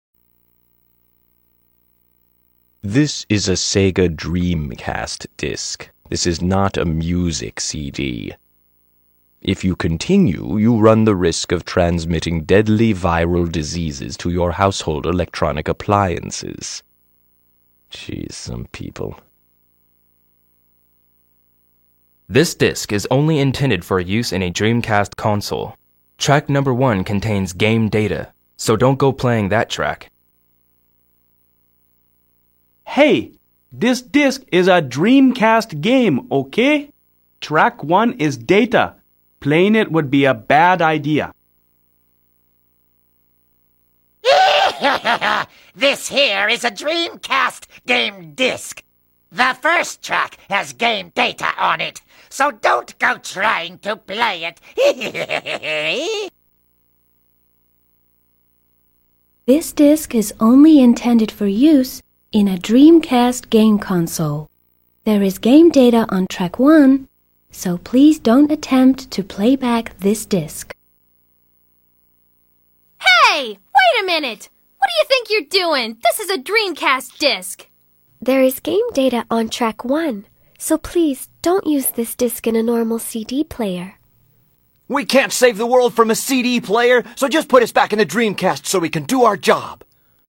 Επίσης, μερικά δισκάκια θα έπαιζαν μια προειδοποίηση που ήταν ηχογραφημένη από χαρακτήρες του παιχνιδιού.
Special-Dreamcast-Game-Disc-Warnings-English.mp3